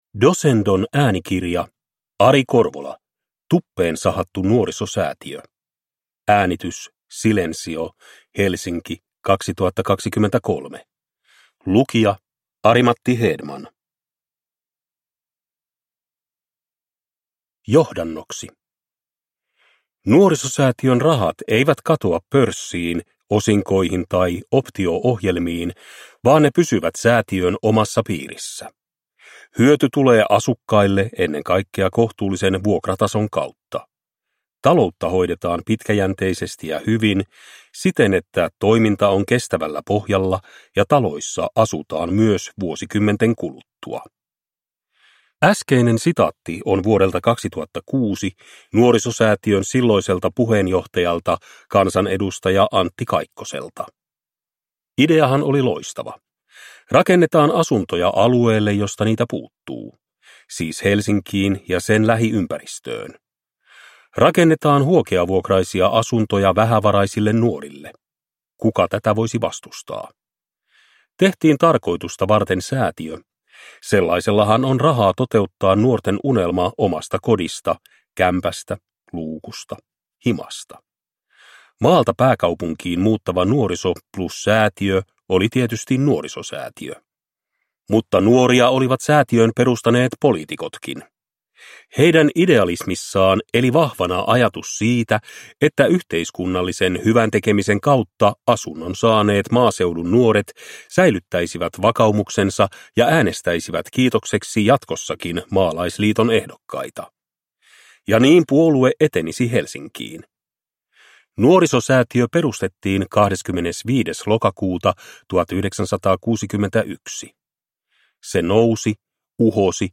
Tuppeen sahattu Nuorisosäätiö – Ljudbok – Laddas ner